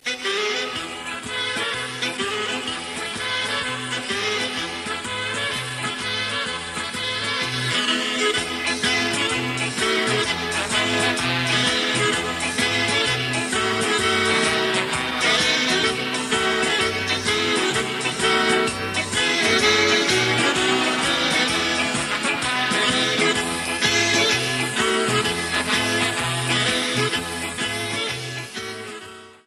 Gattung: Medley
A4 Besetzung: Blasorchester PDF
Großes 2-teiliges Medley im Big-Band-Stil.